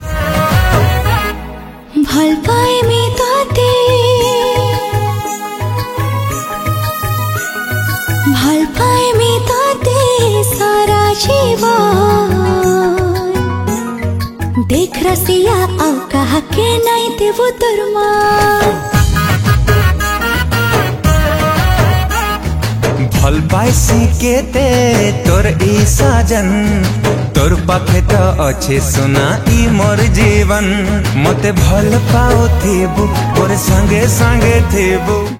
Odia Album Ringtone
Romantic song